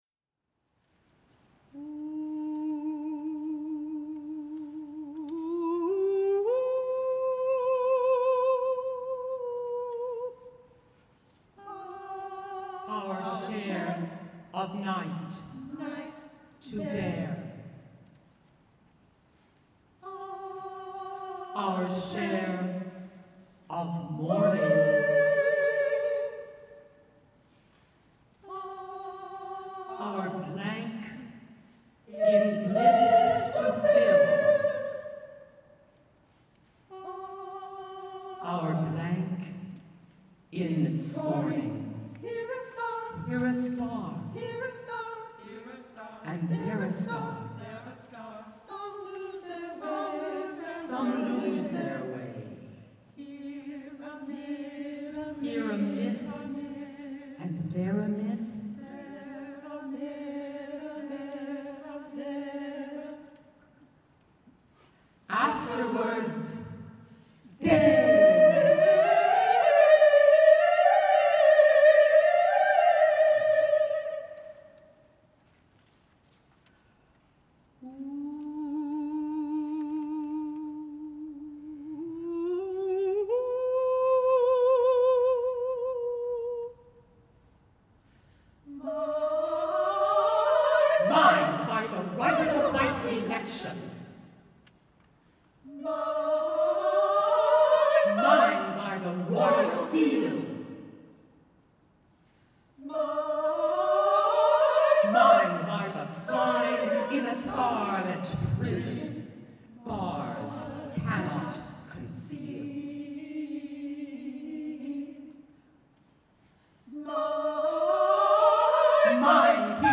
singer/narrator & SA chorus 6 min